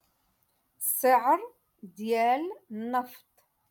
Moroccan Dialect - Rotation Two- Lesson Fifty One